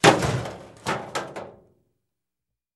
Звуки мусора
Шум выкинутого пакета с мусором